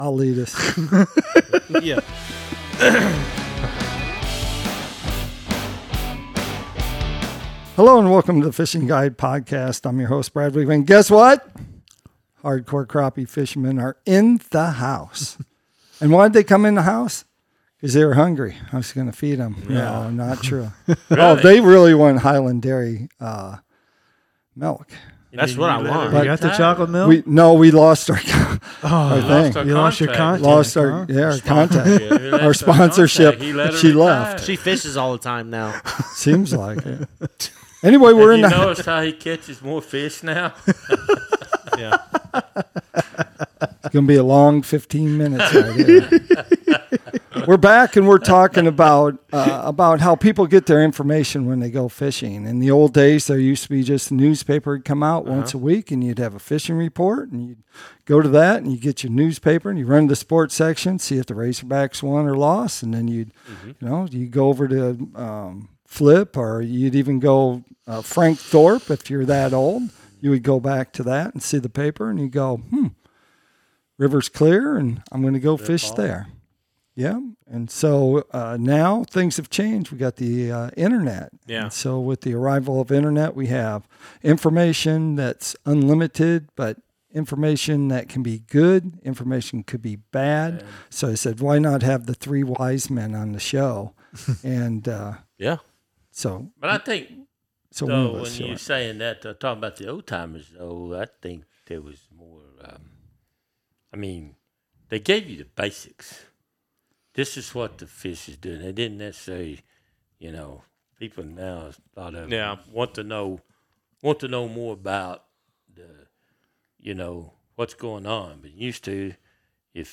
Hardcore Crappie Fishing Beaver Lake fishing guides discuss social media & catching crappie in muddy water conditions